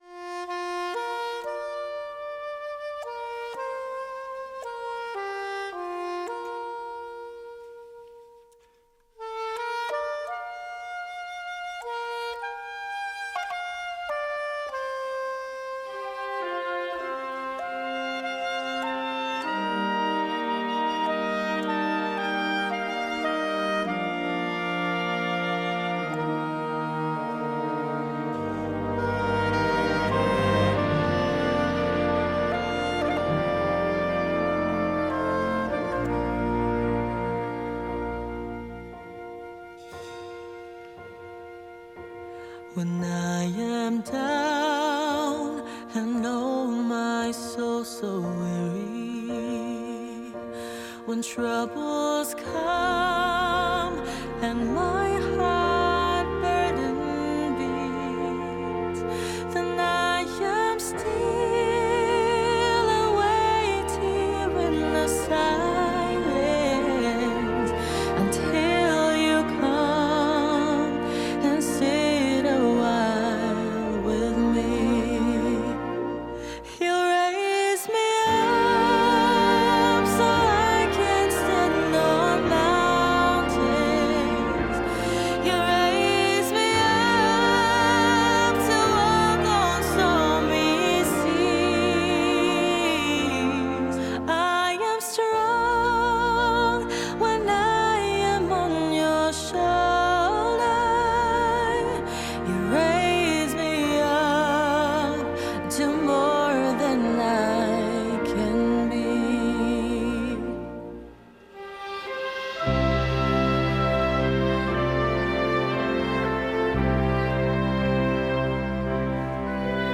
Solo für Gesang und Blasorchester